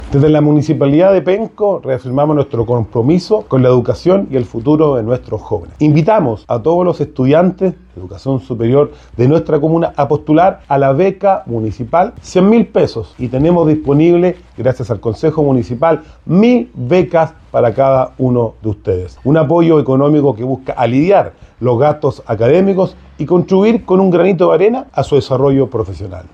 El alcalde de la comuna, Rodrigo Vera, destacó la importancia de este apoyo financiero, subrayando el compromiso de la municipalidad con la educación como pilar fundamental del desarrollo.